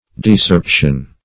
Search Result for " decerption" : The Collaborative International Dictionary of English v.0.48: Decerption \De*cerp"tion\, n. 1. The act of plucking off; a cropping.